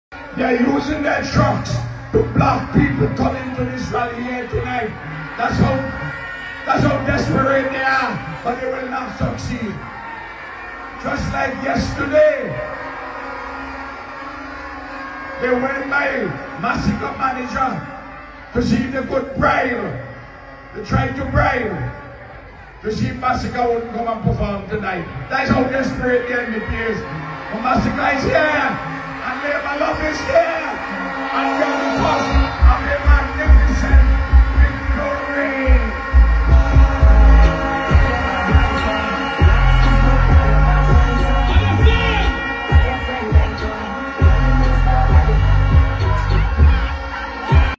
Speaking moments ago, Gonsalves told supporters that vehicles had allegedly been positioned along certain routes leading to the event, making it difficult for young people to reach the rally grounds.
“This is what desperation looks like,” Gonsalves said, addressing the crowd.